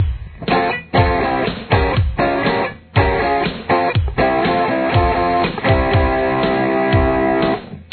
Guitar 2
This guitar’s high E is tuned down one tone to D.